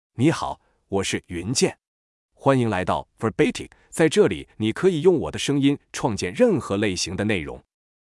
YunjianMale Chinese AI voice
Yunjian is a male AI voice for Chinese (Mandarin, Simplified).
Voice sample
Listen to Yunjian's male Chinese voice.
Male